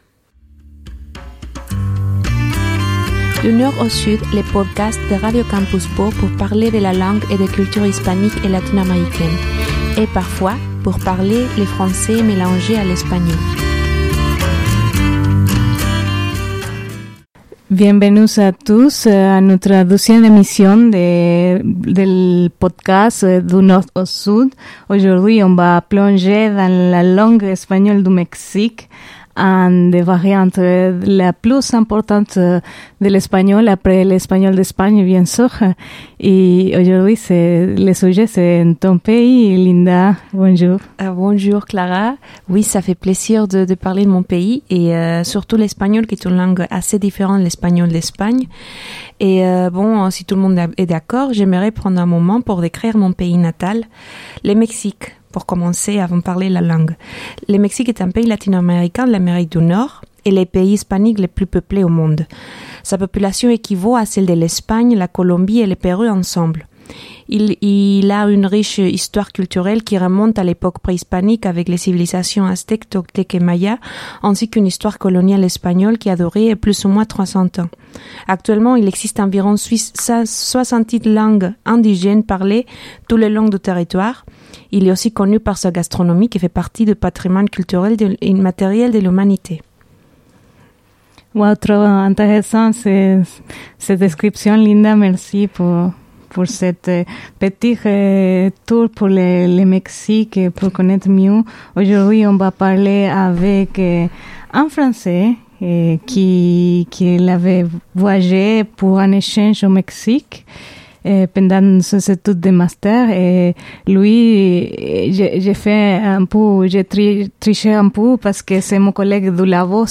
Celui d'un étudiant français qui y a voyagé pendant ses études de Master